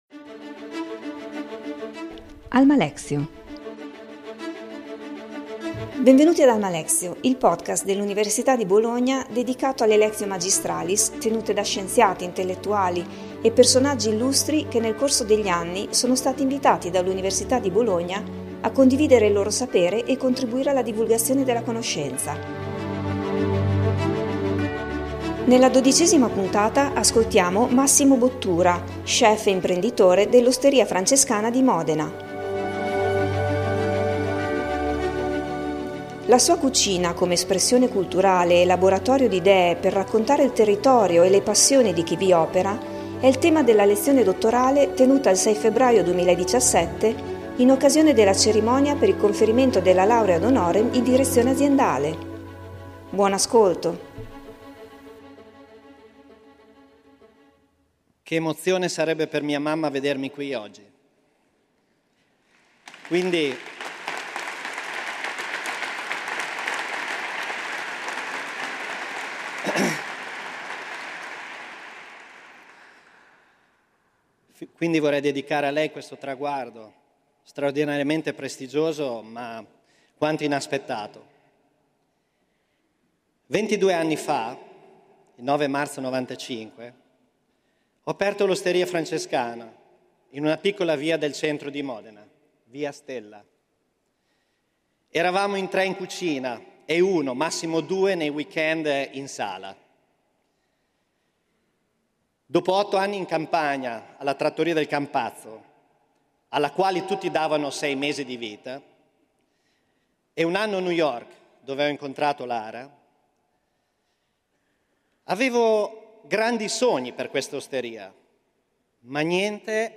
Lo chef e imprenditore dell’Osteria Francescana di Modena, miglior ristorante del mondo nella lista World's 50 Best Restaurants 2016, ha ricevuto all'Alma Mater la laurea ad honorem in Direzione Aziendale il 7 febbraio 2017. Massimo Bottura rappresenta un caso esemplare di gestione di una piccola impresa familiare italiana, raggiungendo in pochi anni un successo senza precedenti e una notorietà a livello globale.